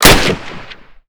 fire_REPLACEME.wav